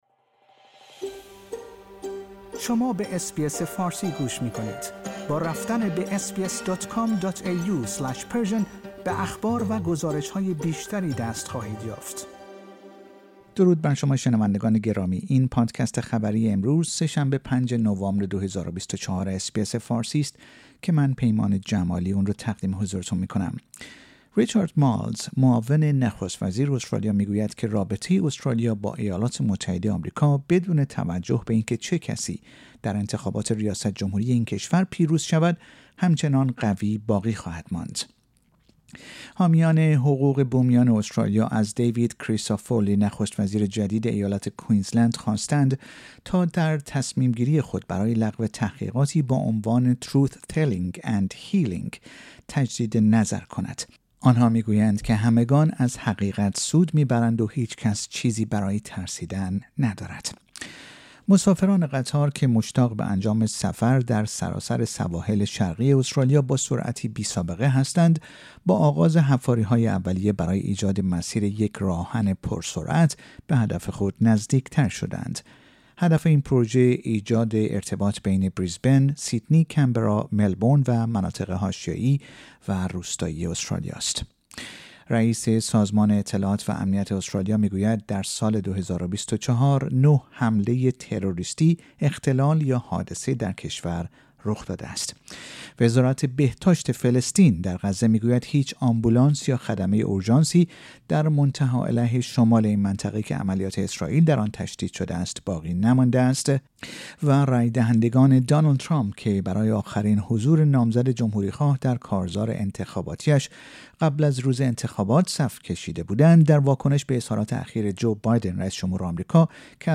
در این پادکست خبری مهمترین اخبار استرالیا در روز سه شنبه ۵ نوامبر ۲۰۲۴ ارائه شده است.